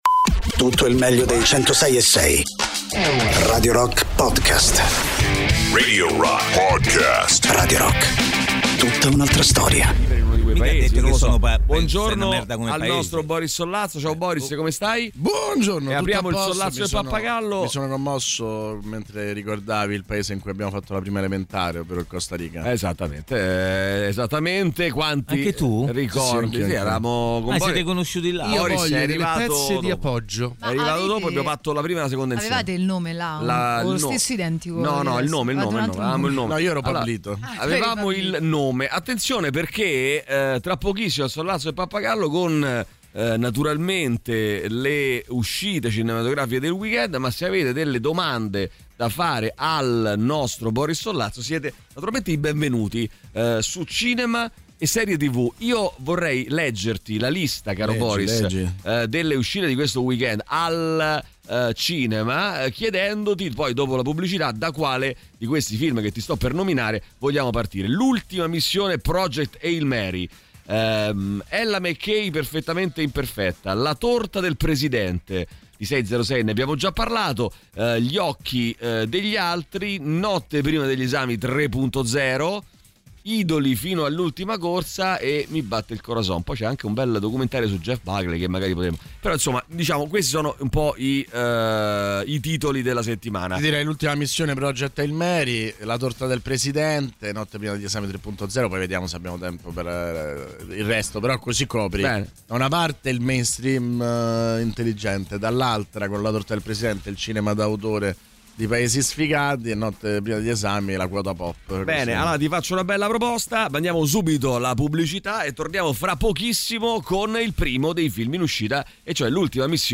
il venerdì, durante l’ultima ora del The Rock Show, sui 106.6 di Radio Rock.